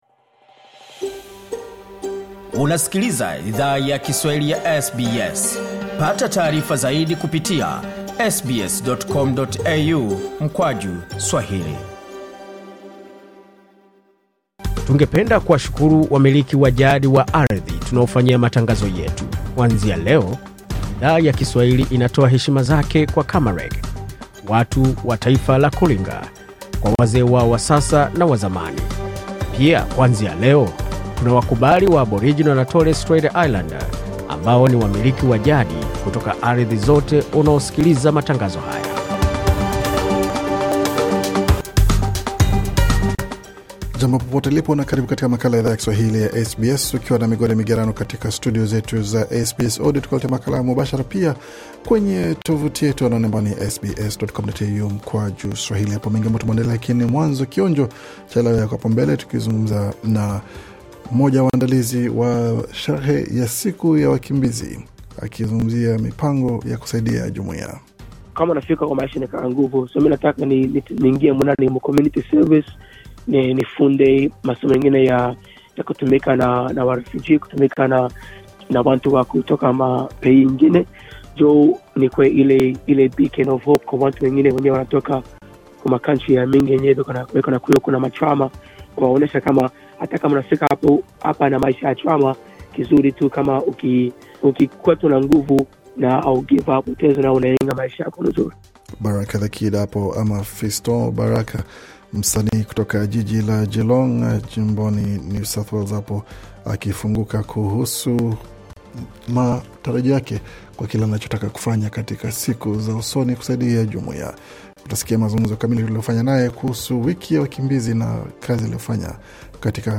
Taarifa ya Habari 20 Juni 2025